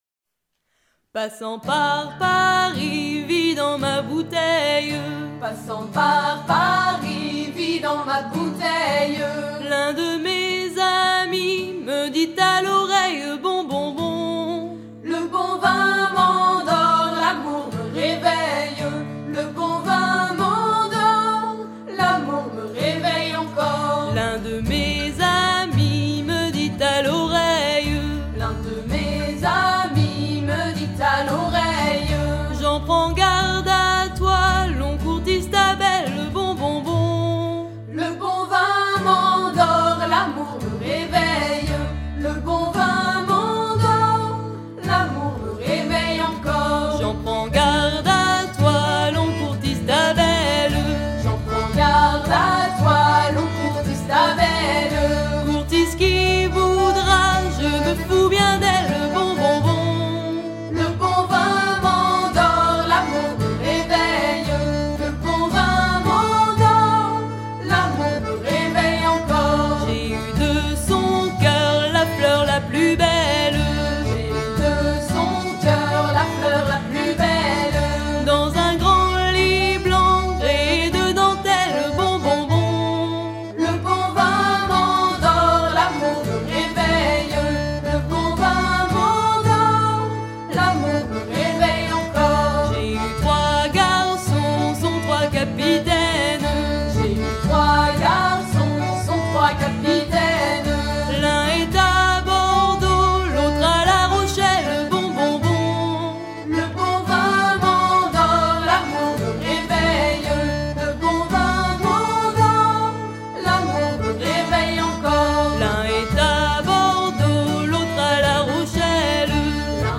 à virer au cabestan
Pièce musicale éditée